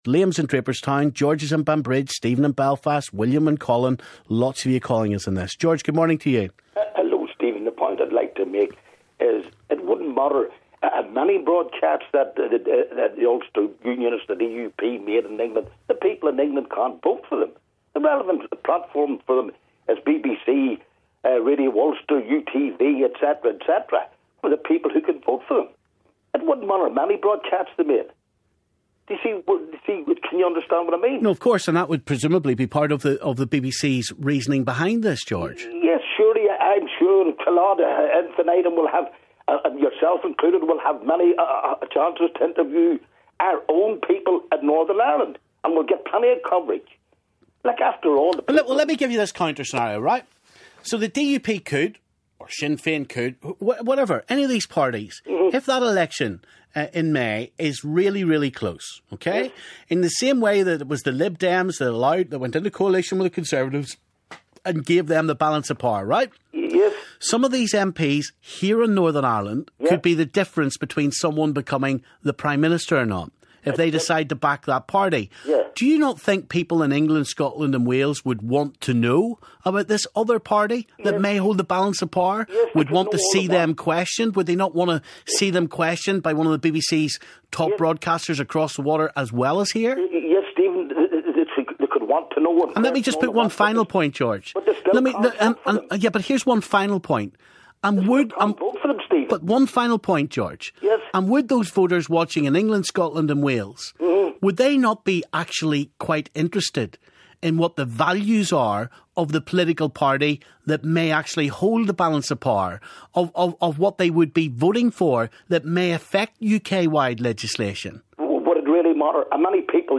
Callers react to NI exclusion from election debate
Callers react to the news that The BBC has rejected the DUP's demand to be included in the TV debate.